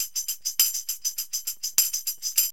TAMB LP 102.wav